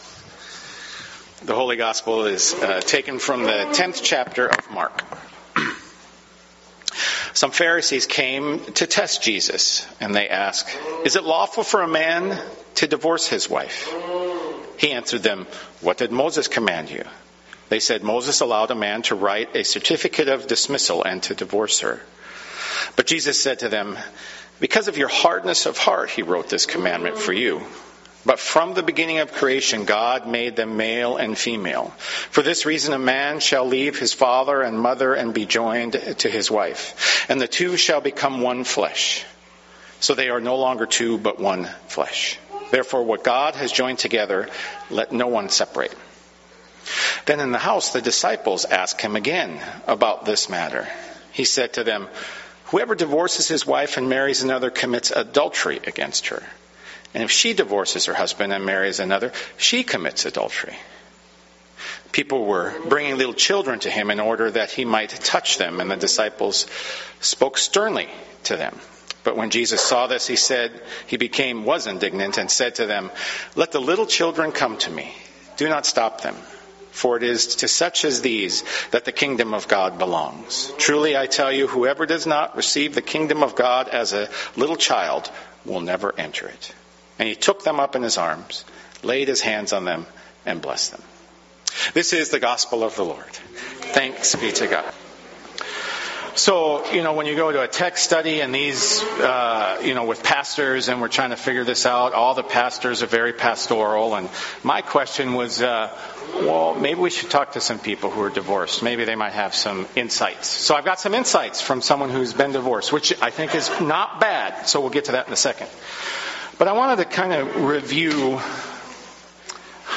click on the link below. 19th Sunday after Pentecost